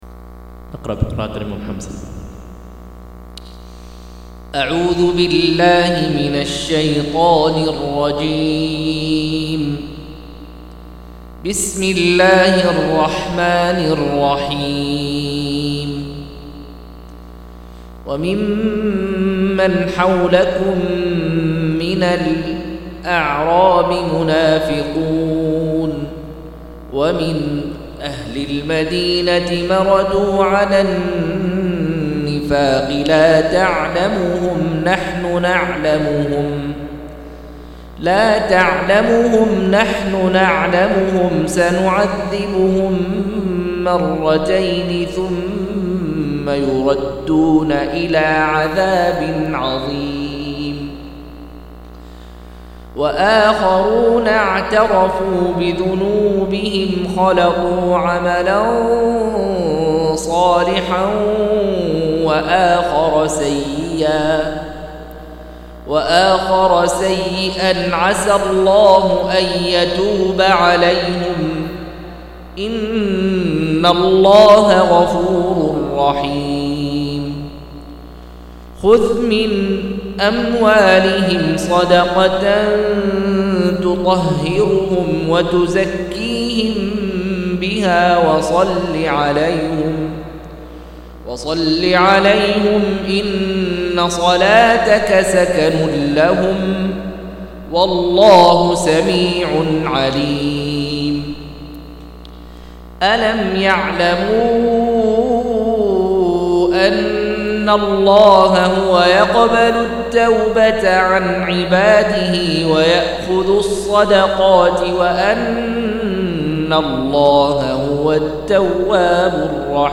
192- عمدة التفسير عن الحافظ ابن كثير رحمه الله للعلامة أحمد شاكر رحمه الله – قراءة وتعليق –